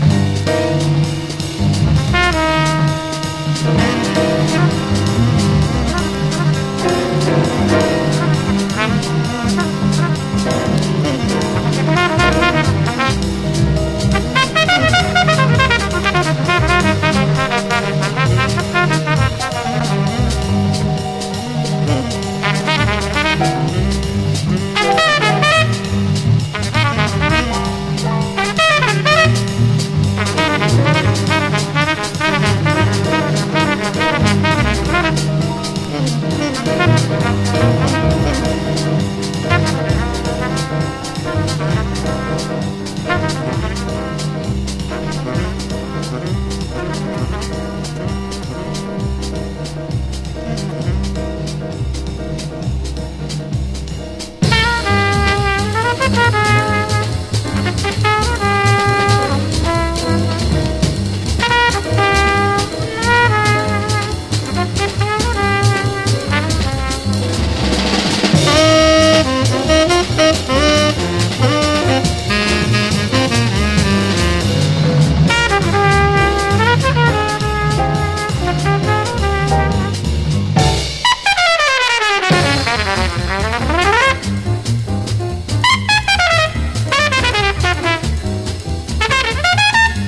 ピュンピュン音入りのラテン風味アーバン・ディスコ・ブギーがナイス！